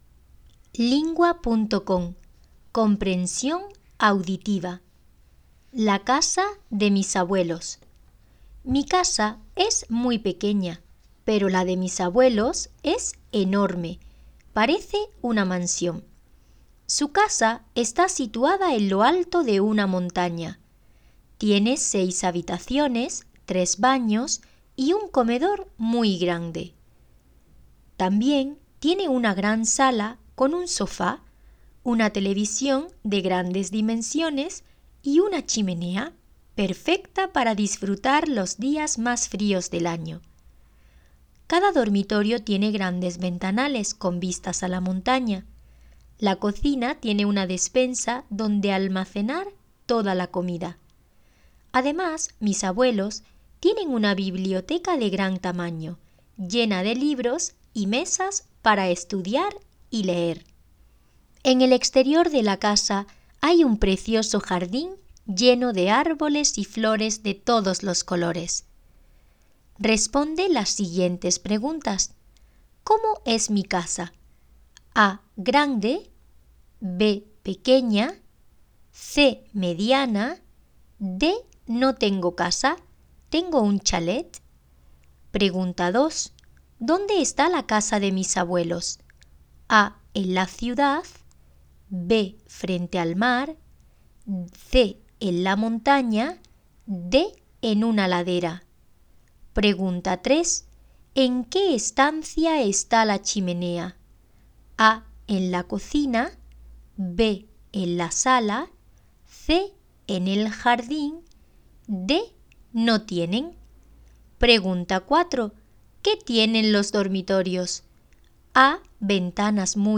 Spanien